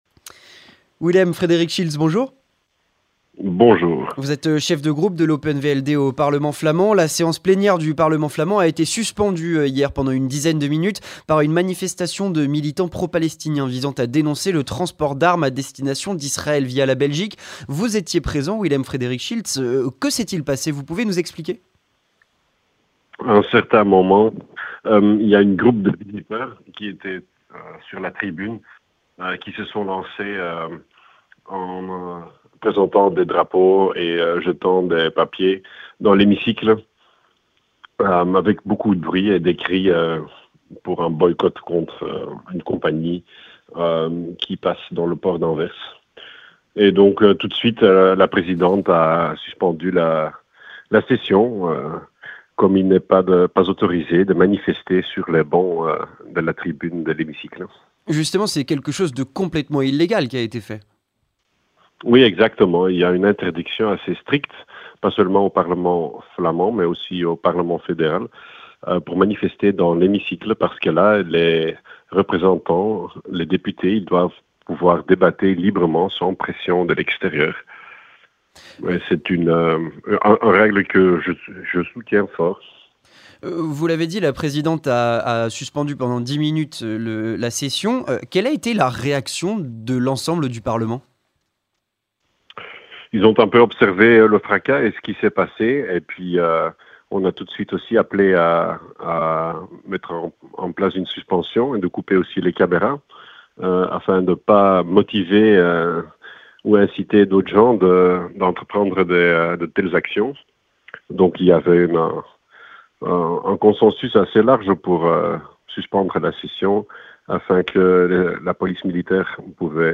Avec Willem Frederik Schiltz, chef de groupe Open vld au parlement flamand